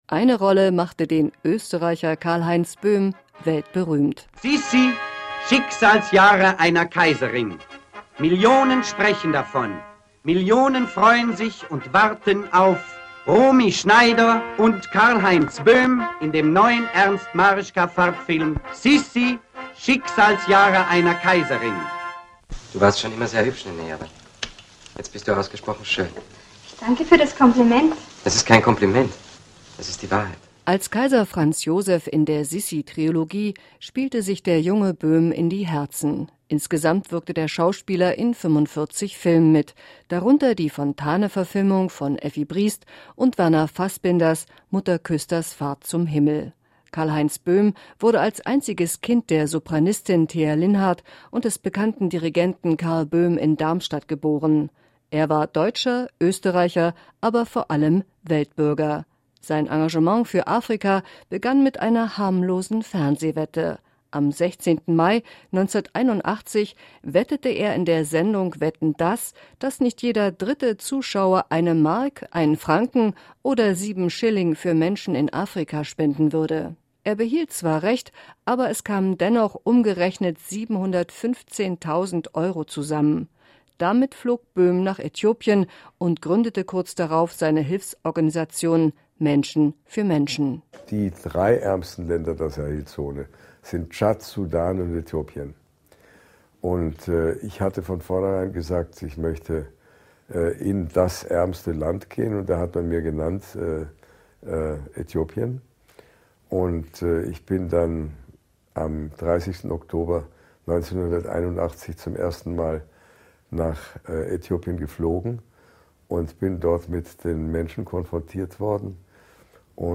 Karlheinz-Boehm-ist-tot.-Nachruf.mp3